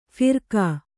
♪ phirkā